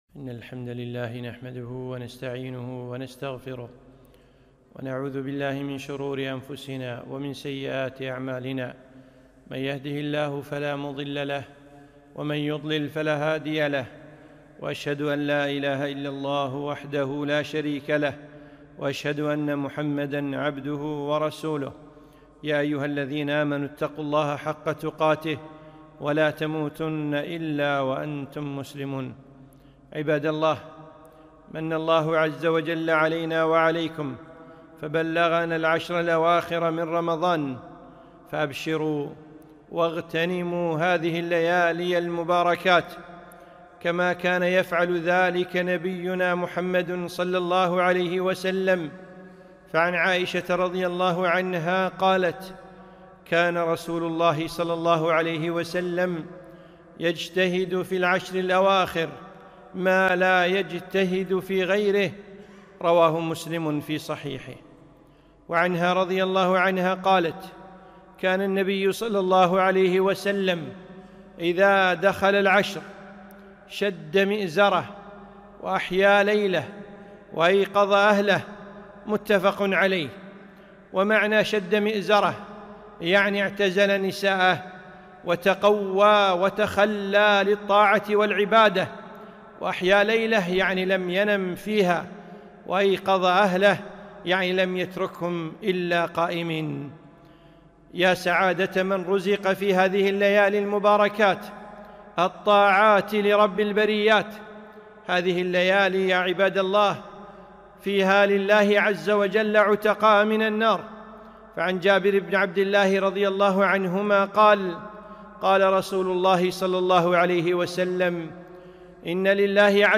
خطبة - اغتنموا